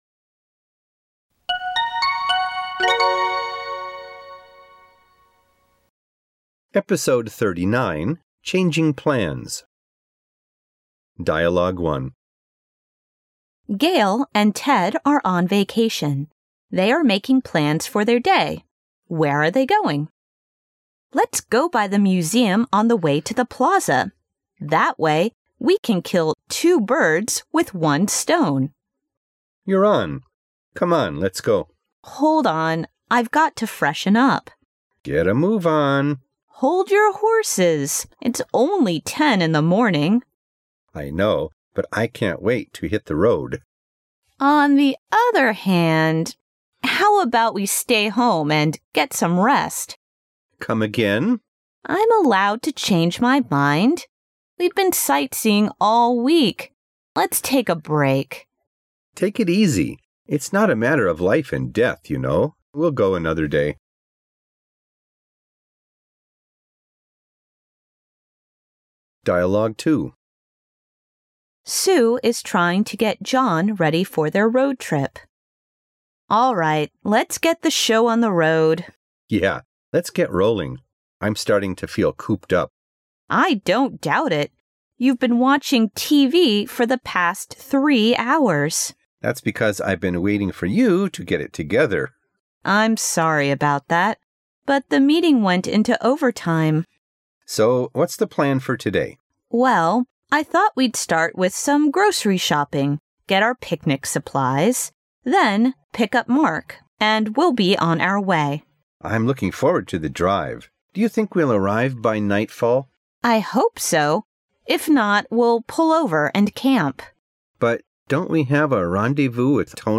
它设计了60个场景，每个场景包含两组对话，内容涉及众多日常生活场景和工作场景，对话语言地道新潮，相关文化背景知识介绍，让你将文化学习与语言学习融为一体，如同亲临现代美国社会，全方位学习地道的英语口语表达。